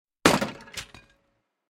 guard-crash.ogg.mp3